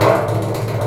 Index of /90_sSampleCDs/Roland L-CD701/PRC_FX Perc 1/PRC_Long Perc